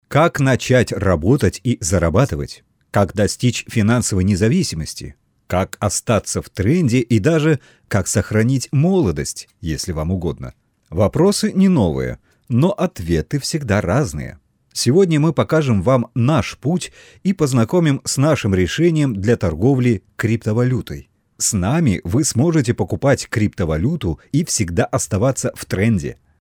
Обладаю мягким, неагрессивным баритоном, подходящим для множества задач.
внешняя звуковая карта M-AUDIO микрофон AUDIO-TECHNICA AT-4033 поп-фильтр, кабинет